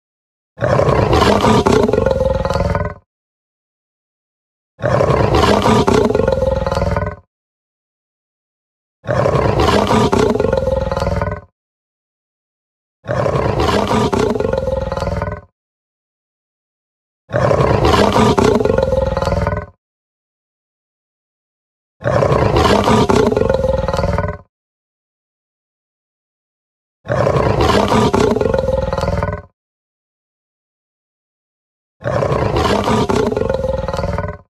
Se trata de un efecto que nos permite distinguir si un sonido viene de la derecha o de la izquierda.
Ejemplo de paneo
ejemplo-paneo-relatos-sonoros.wav